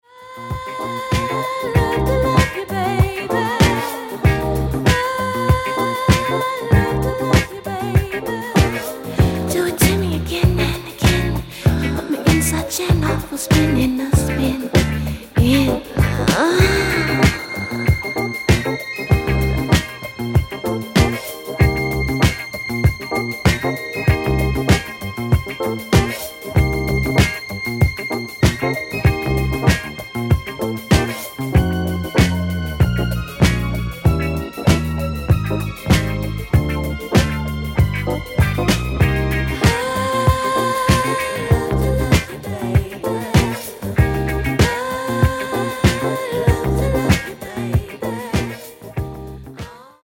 Genere:   Disco | Pop | New Wave